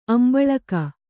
♪ ambaḷaka